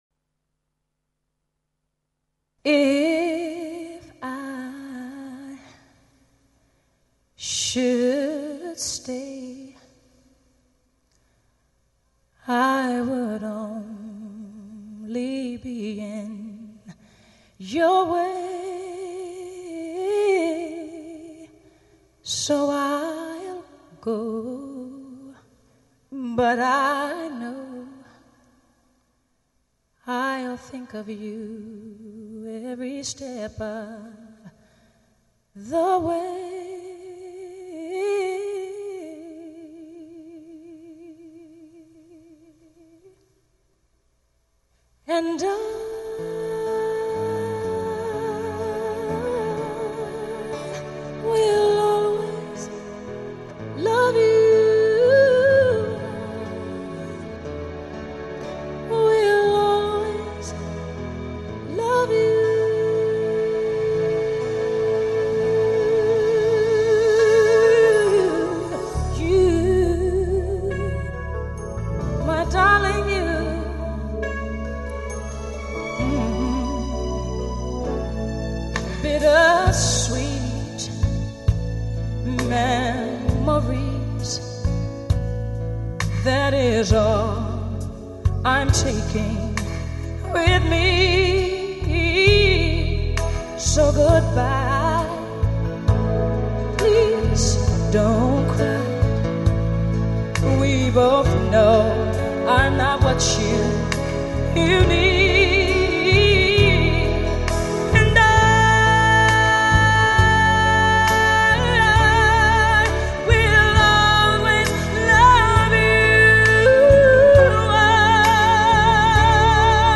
曲、爵士乐、电影原声、世界名曲、中国名曲等篇章完美结合，空灵飘渺，轻柔欣感中不失
浓烈的情感色彩、令人心旷神怡。